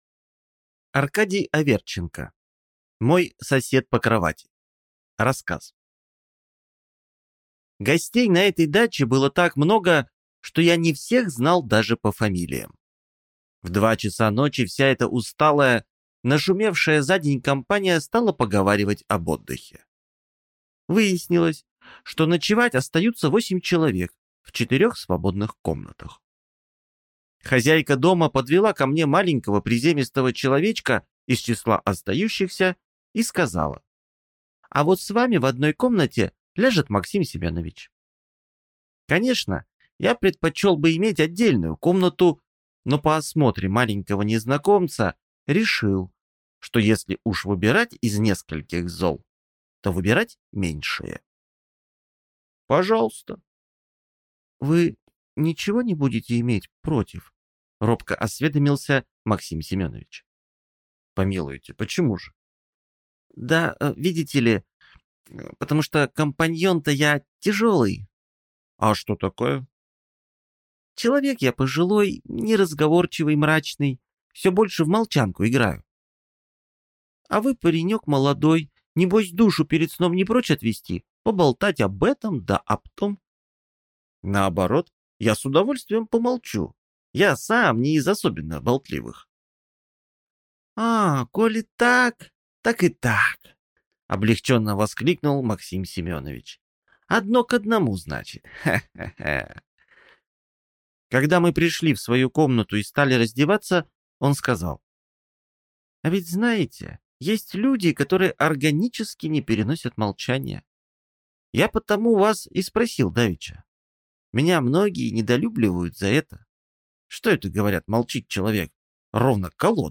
Аудиокнига Мой сосед по кровати | Библиотека аудиокниг